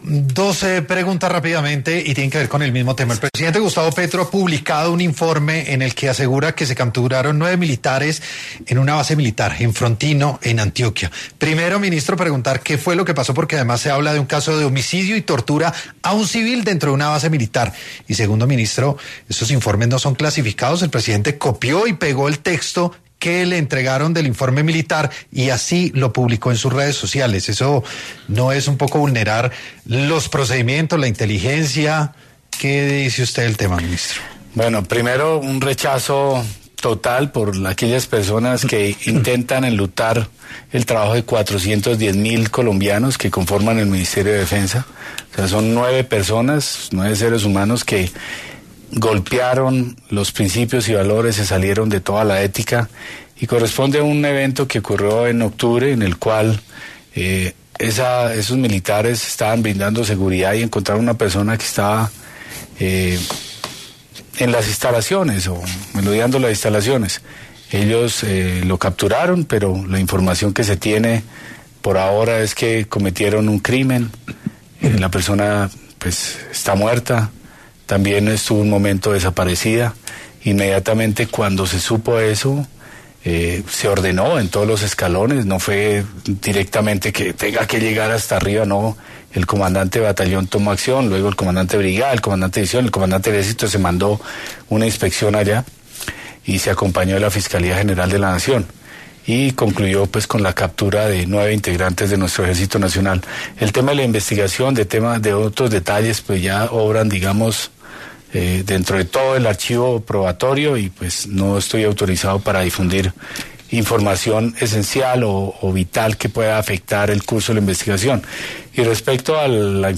El ministro de Defensa, Pedro Sánchez, habló ‘Sin Anestesia’ en ‘La Luciérnaga’ sobre el hecho violento que involucró a nueve militares en la tortura, homicidio y desaparición forzada de un civil dentro de un batallón.
En esta coyuntura, el ministro de Defensa, Pedro Sánchez, pasó por los micrófonos de ‘La Luciérnaga’ para hablar sobre este caso.